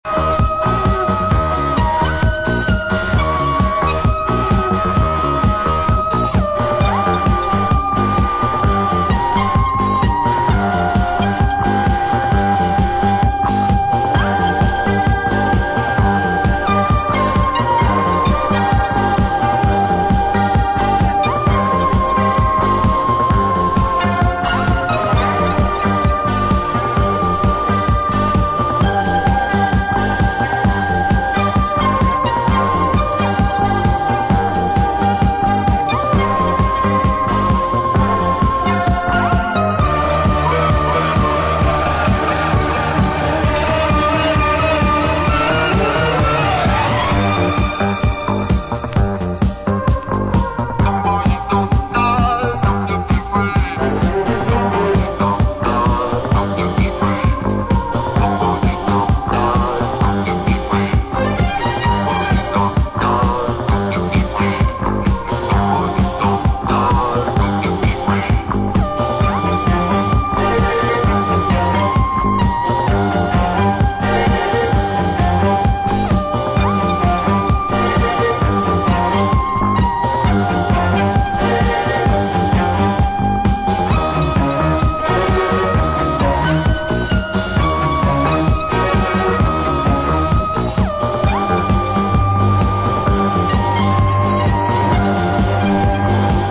Another compilation of disco hits and oddities